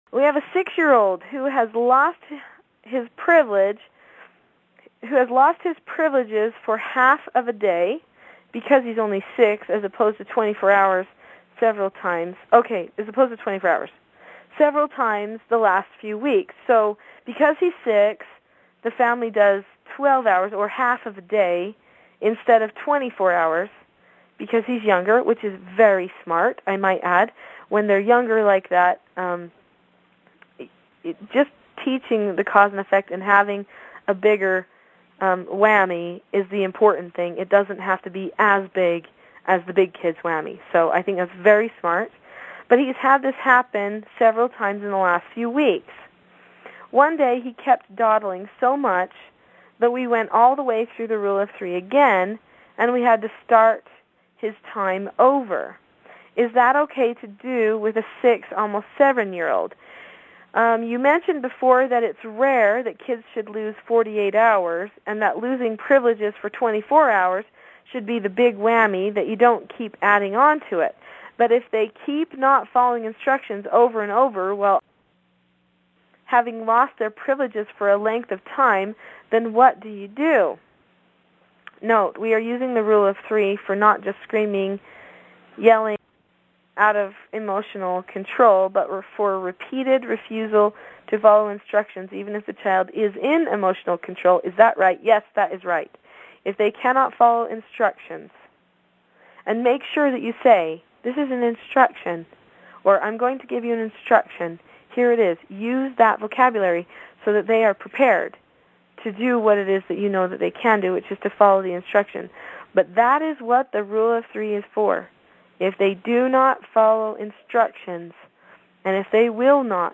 Recently, a woman wrote me a question to be answered on one of the weekly conference calls I do for the Implementation Course members about how to help her son stop having tantrums.This is my answer to her question. The first part of this short audio segment is me reading her question, then I answer it.